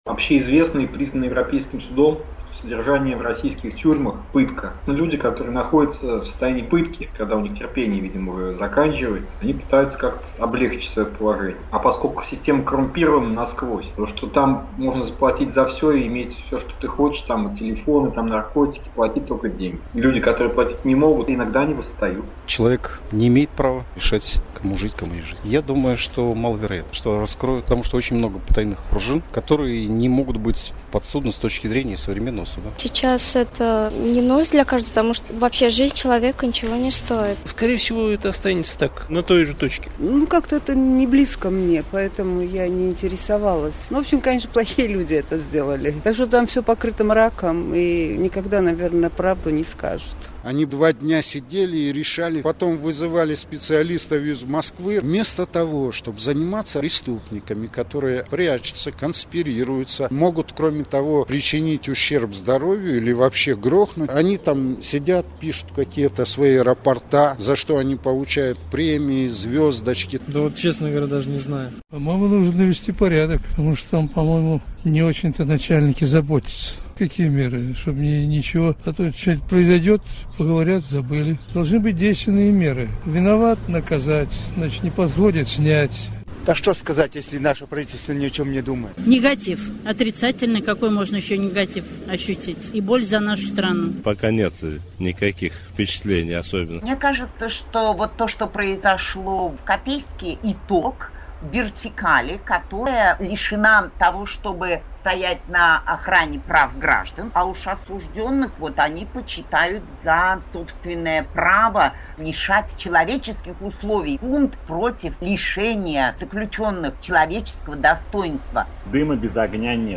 опрос на улицах Самары